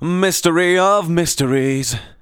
Index of /90_sSampleCDs/Techno_Trance_Essentials/VOCALS/SUNG/C#-BAM